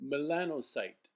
Pronunciation/məˈlænəˌst, -n-/
En-melanocyte.oga.mp3